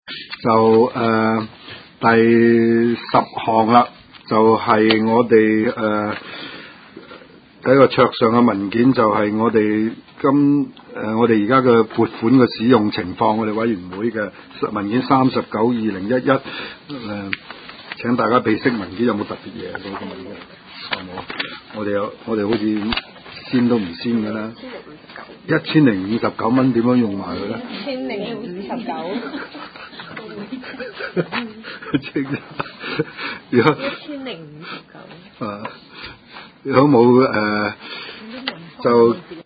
灣仔民政事務處區議會會議室